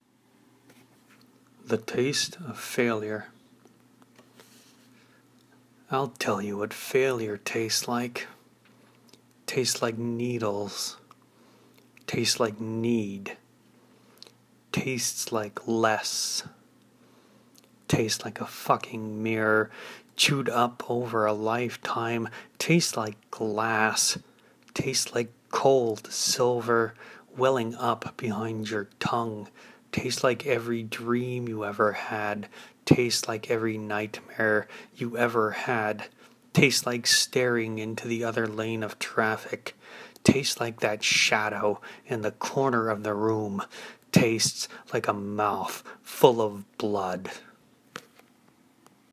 1. Record yourself reading one of your own works.